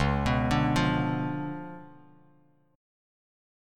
C#7b9 chord